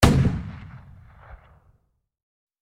cannonShoot.wav